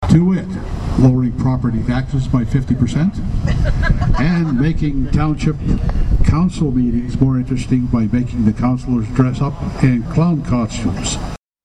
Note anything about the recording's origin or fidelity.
The Third Annual Arnprior Lions Jail and Bail had more comedy and drama than ever before, as the fundraiser nestled into the Giant Tiger Parking Lot Saturday.